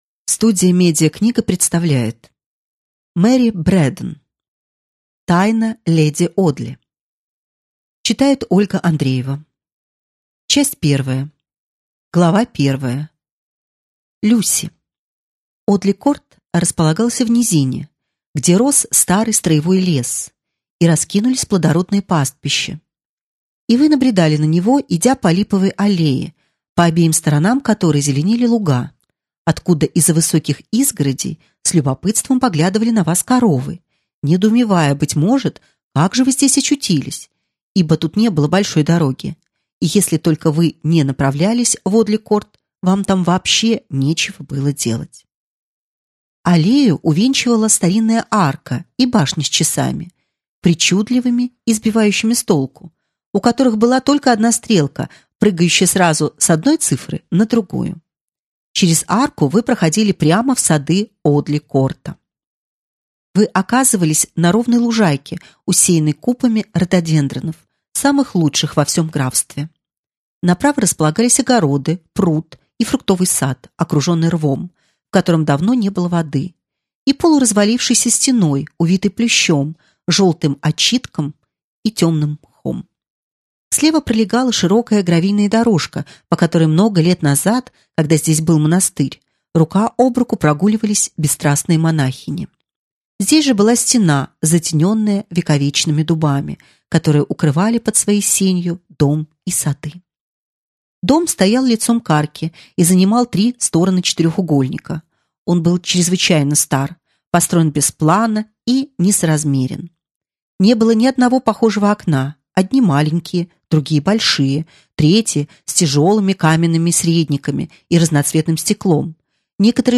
Аудиокнига Тайна леди Одли | Библиотека аудиокниг